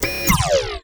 alaser.wav